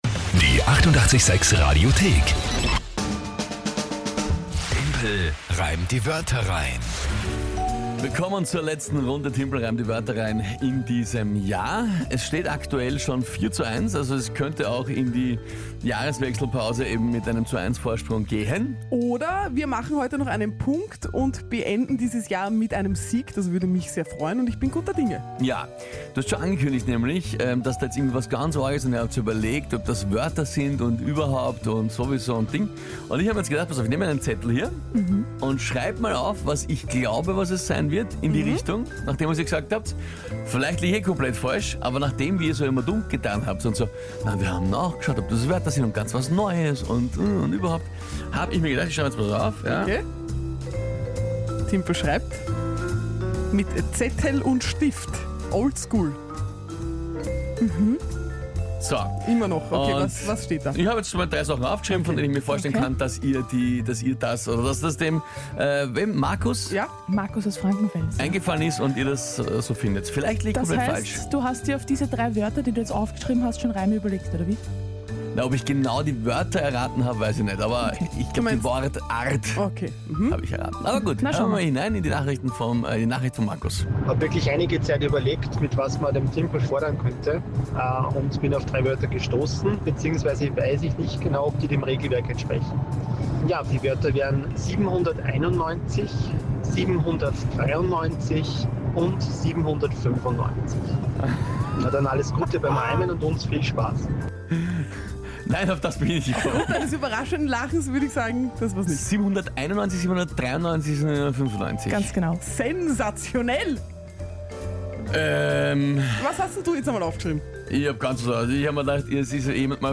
Der Timpel muss es schaffen, innerhalb von 30 Sekunden, drei von einem Hörer vorgegebenen Wörter zu einem aktuellen Tagesthema in einen Reim einzubauen.